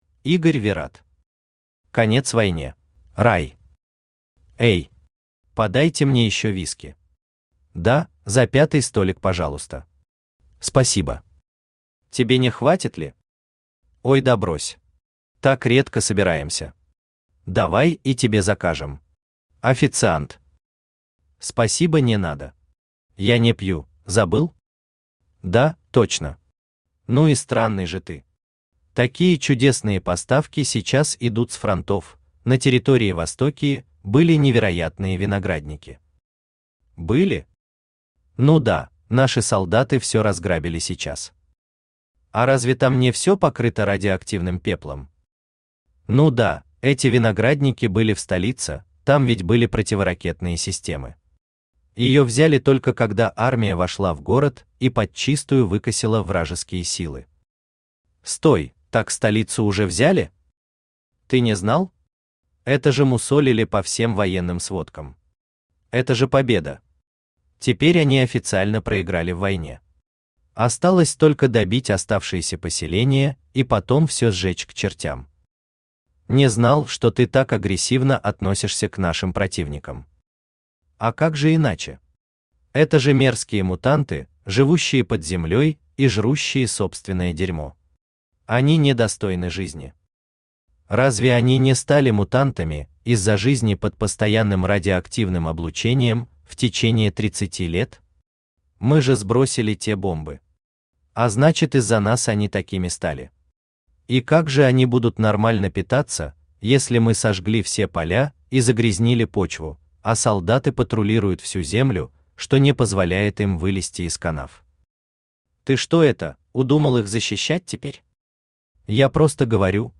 Аудиокнига Конец войне | Библиотека аудиокниг
Aудиокнига Конец войне Автор Игорь Анатольевич Вират Читает аудиокнигу Авточтец ЛитРес.